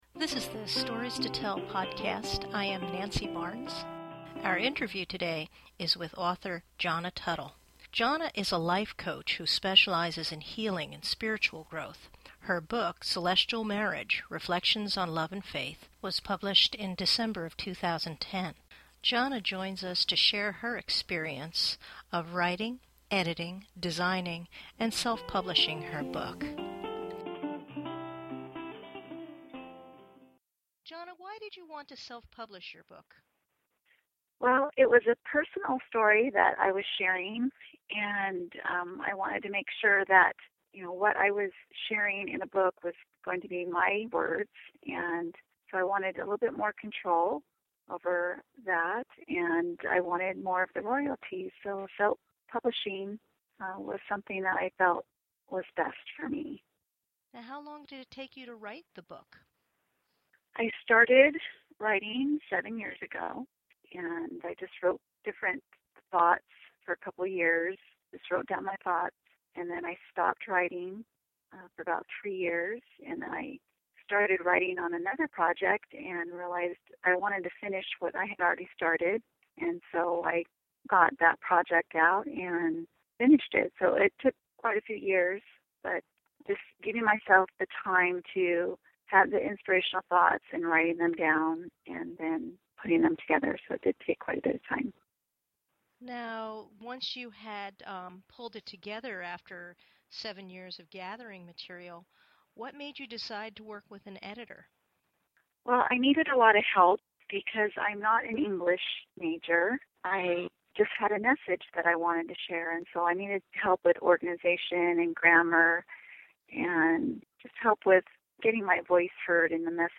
Self Publishing Interview